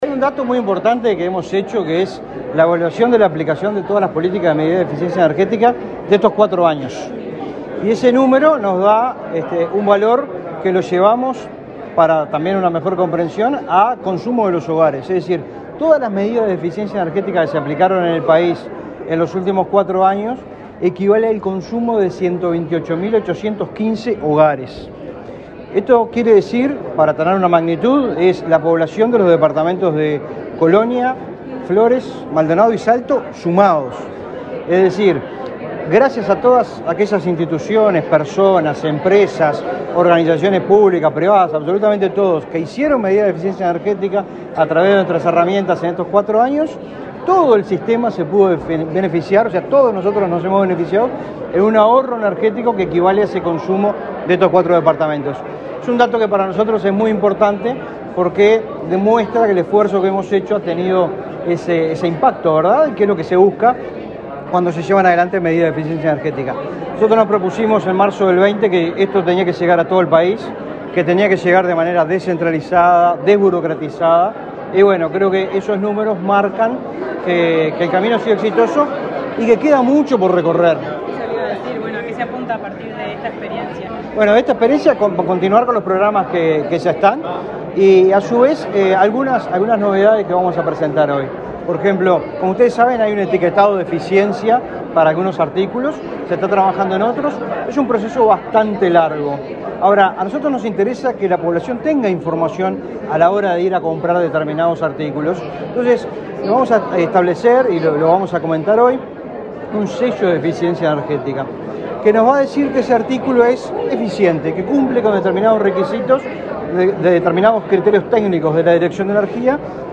Declaraciones del director nacional de Energía, Fitzgerald Cantero
Declaraciones del director nacional de Energía, Fitzgerald Cantero 15/03/2024 Compartir Facebook X Copiar enlace WhatsApp LinkedIn El director nacional de Energía, Fitzgerald Cantero, dialogó con la prensa, antes de participar, este viernes 15 en la Torre Ejecutiva, en el lanzamiento anual de herramientas de eficiencia energética.